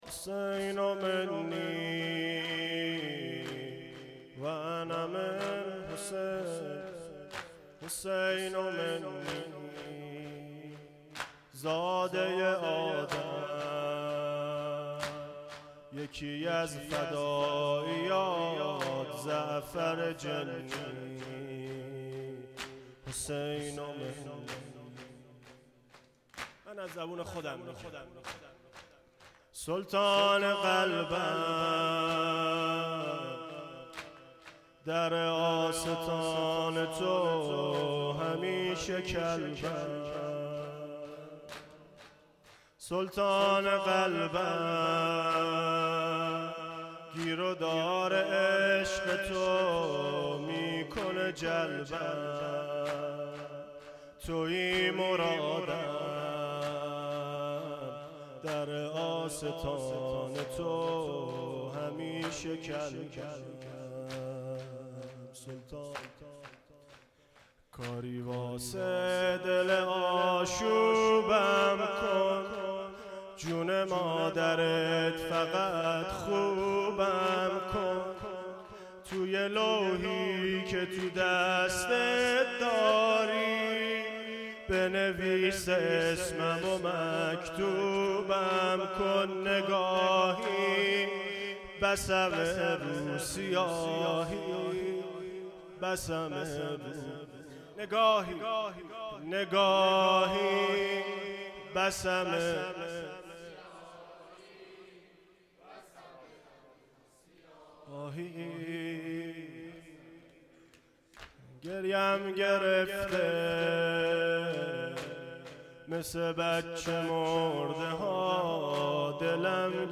پایانی شب چهارم فاطمیه
مداحی